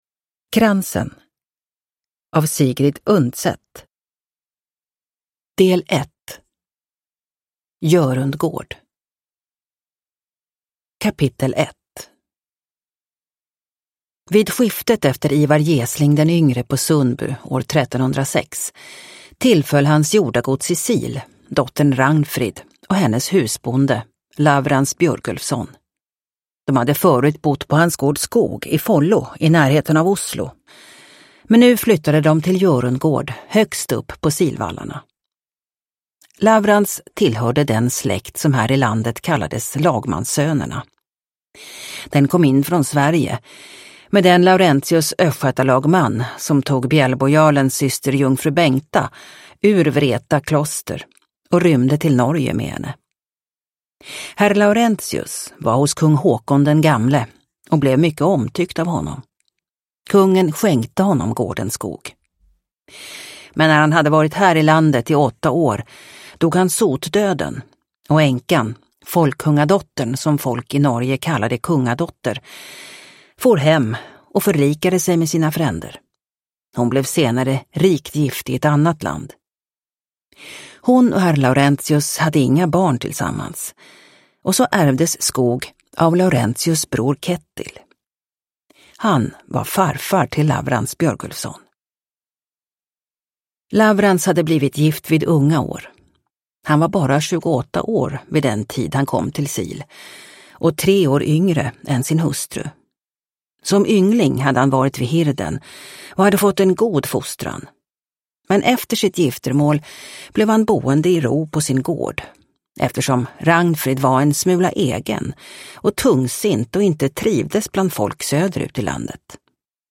Kransen – Ljudbok – Laddas ner